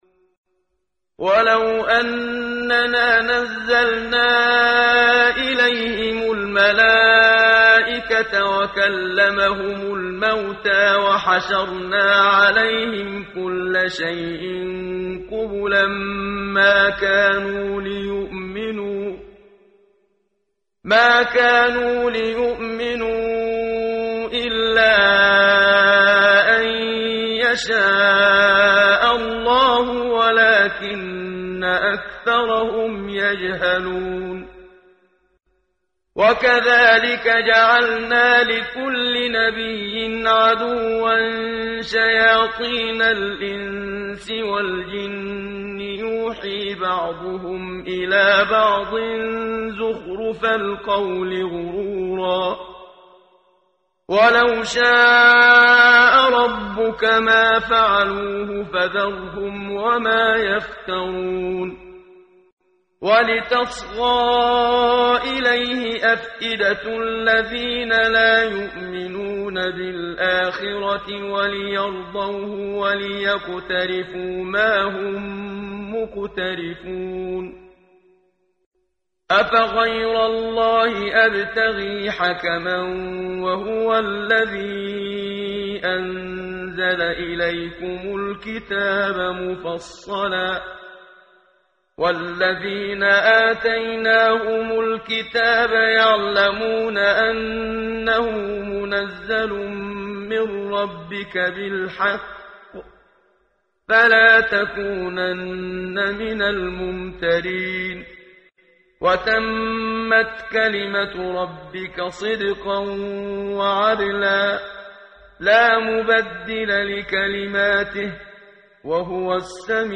ترتیل صفحه 142 سوره مبارکه انعام (جزء هشتم) از سری مجموعه صفحه ای از نور با صدای استاد محمد صدیق منشاوی